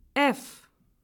En cliquant sur le symbole, vous entendrez le nom de la lettre.
lettre-f.ogg